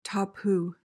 PRONUNCIATION: (TAH-poo) MEANING: noun: A restriction or prohibition; taboo. adjective: Sacred, restricted, or forbidden.